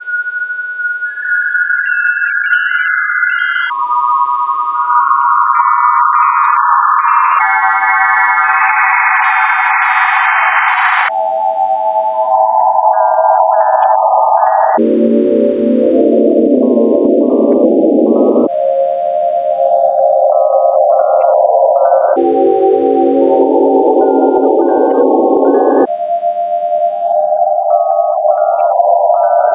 vgm,